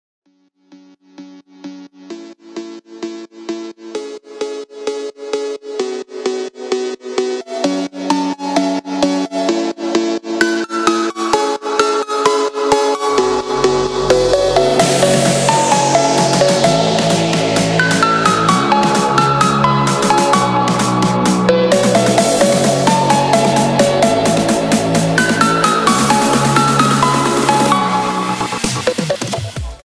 Phonk Music